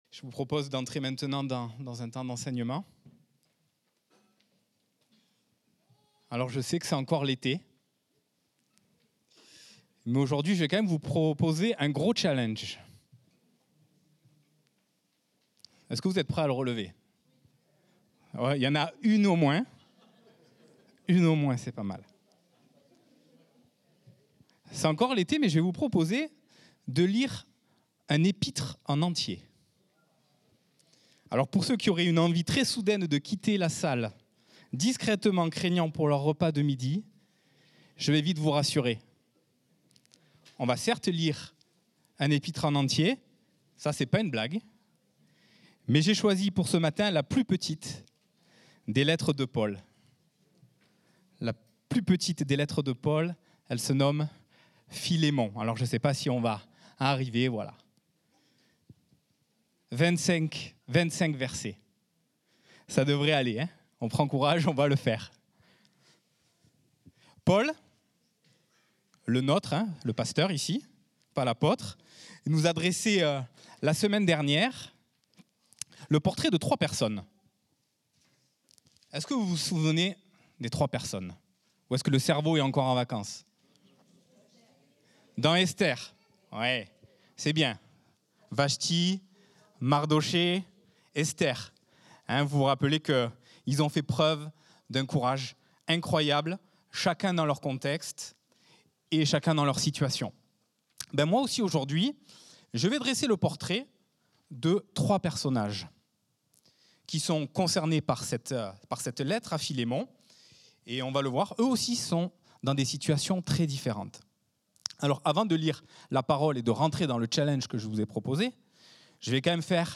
Culte du dimanche 17 août 2025